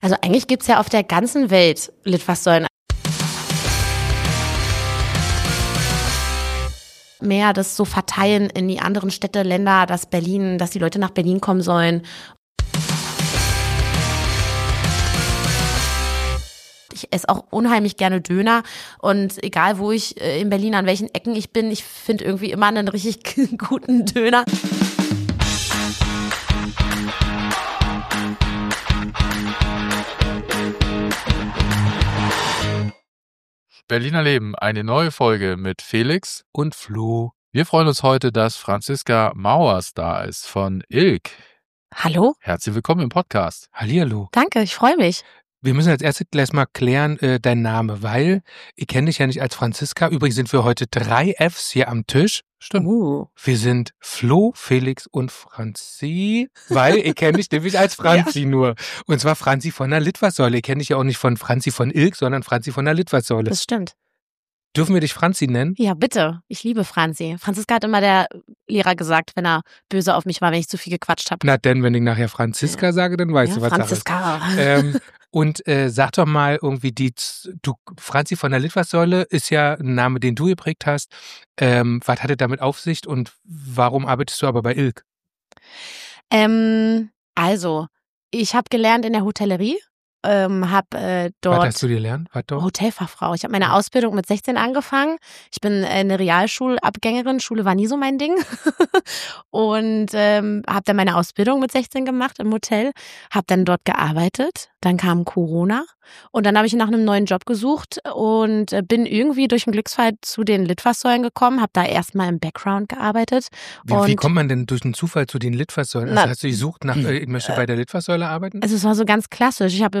Ein sehr unterhaltsames, charmantes, und druchaus auch lehrreiches Berlin-Gespräch über Werbung, Stadtgeschichte und das Leben zwischen Plakaten und Kiez.